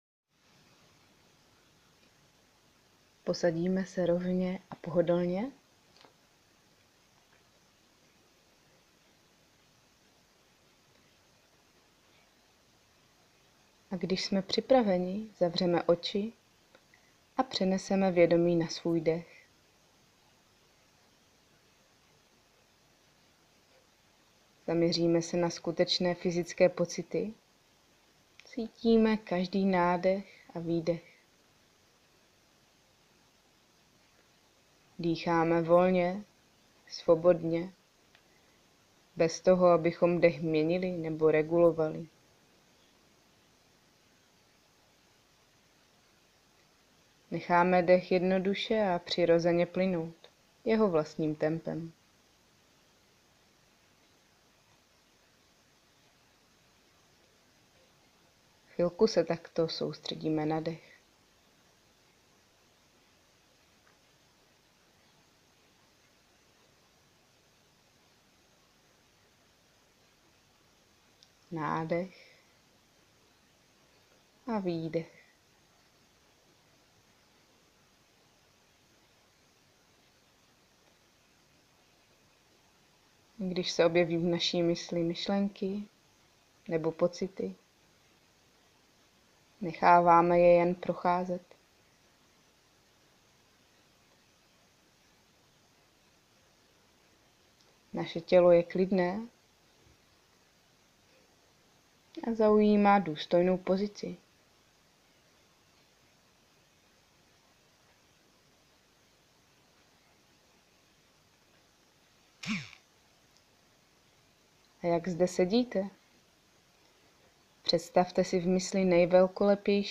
Že chcete v životě více radosti, klidu, nadhledu, sebejistoty…A že to chcete zkusit s námi:) Tato krátká praxe všímavosti spojená s vizualizací se jmenuje „Hora“ . Na praxi je dobré najít si klidné místo, posadit se ideálně s rovnou páteří, oči zavřít, nebo nechat lehce pootevřené.
Snažíme se vědomě stále být s předmětem soustředění – zde s vizualizací podle mluveného textu.